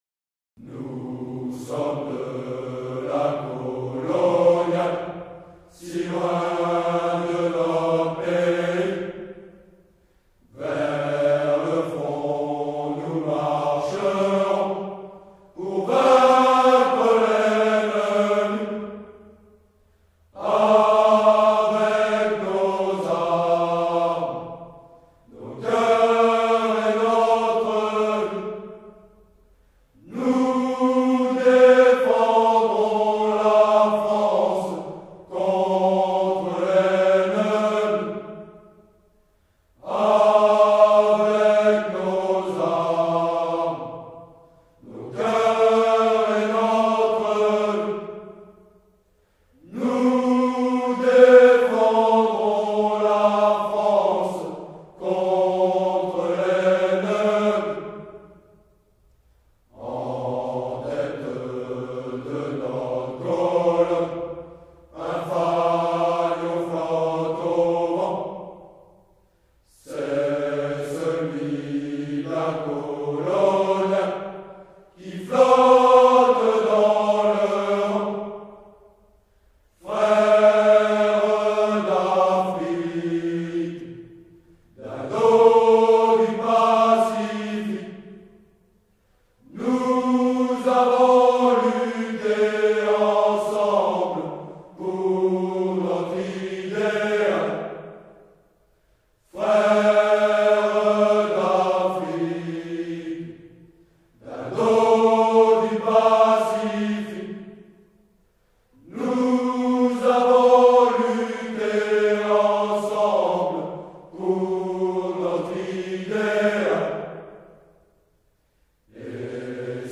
Nous-sommes-de-la-Coloniale-Chant-des-Troupes-de-Marine.mp3